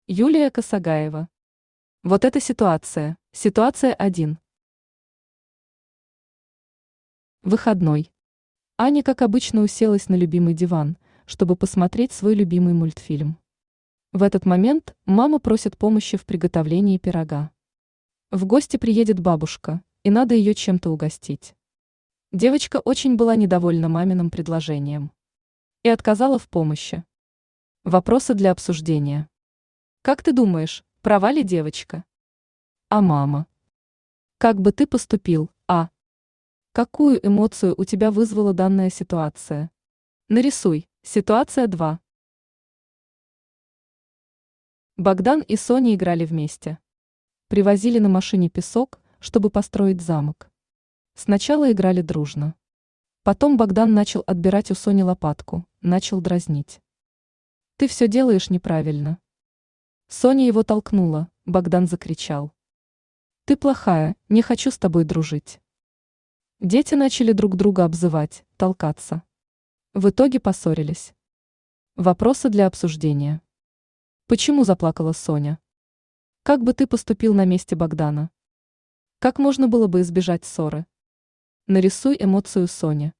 Аудиокнига Вот это ситуация | Библиотека аудиокниг
Aудиокнига Вот это ситуация Автор Юлия Косагаева Читает аудиокнигу Авточтец ЛитРес.